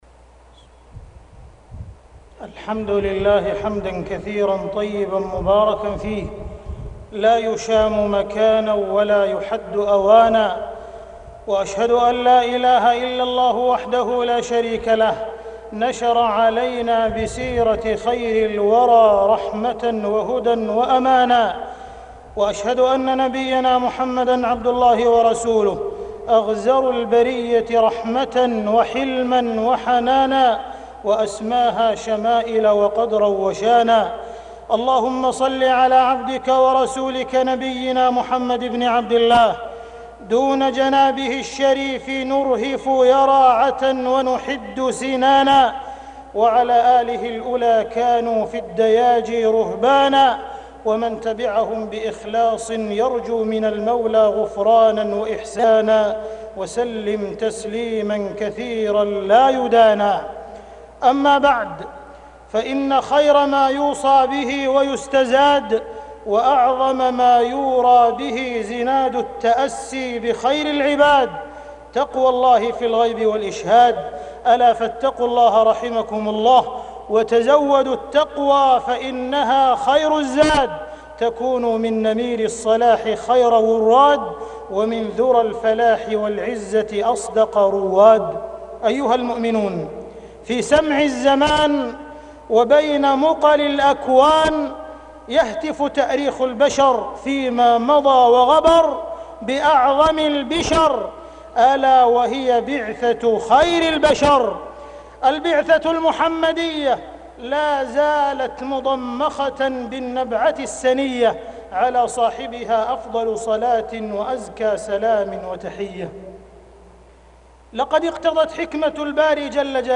تاريخ النشر ٩ ربيع الأول ١٤٢٧ هـ المكان: المسجد الحرام الشيخ: معالي الشيخ أ.د. عبدالرحمن بن عبدالعزيز السديس معالي الشيخ أ.د. عبدالرحمن بن عبدالعزيز السديس مناقب وشمائل الرسول